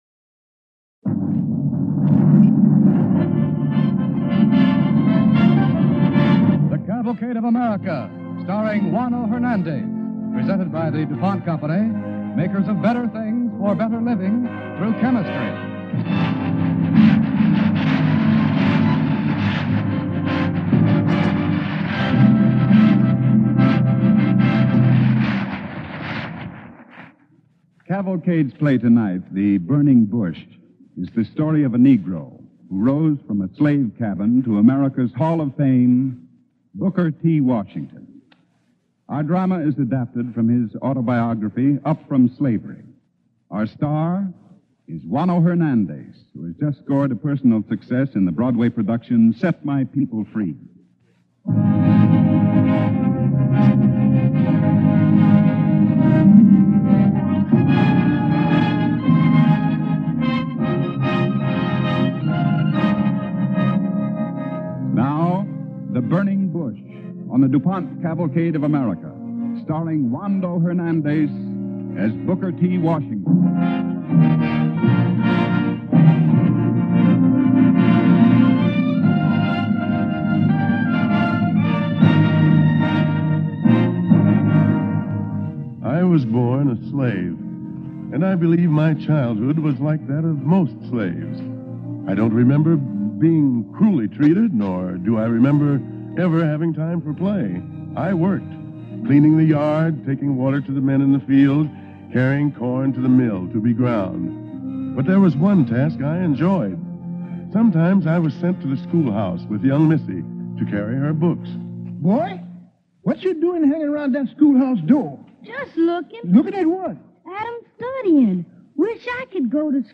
starring Juano Hernandez
Cavalcade of America Radio Program